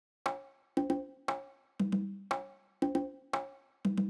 04 Congas.wav